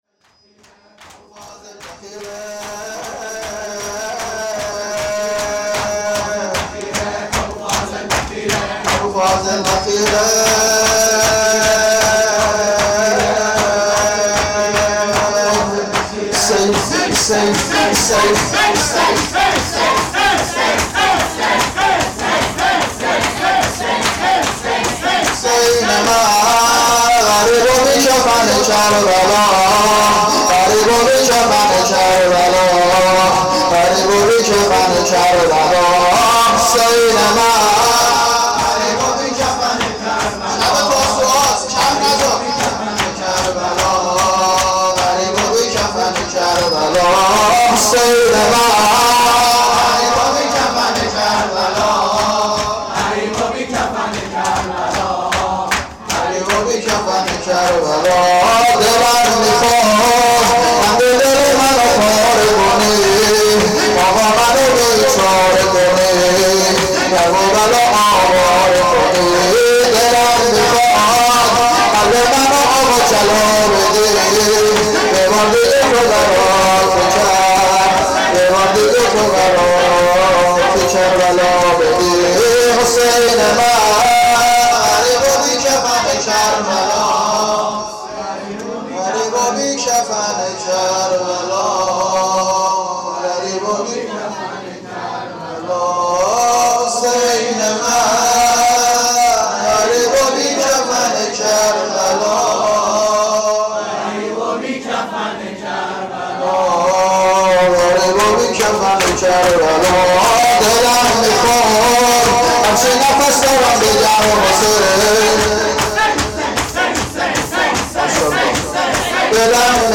• مراسم سینه زنی شب نهم محرم هیئت روضه الحسین